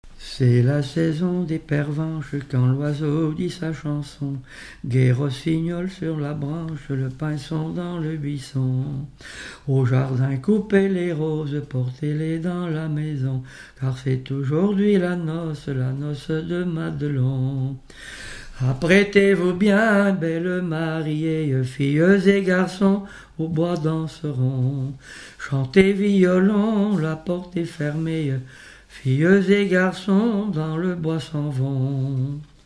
Saint-Etienne-du-Bois
Genre strophique
Pièce musicale inédite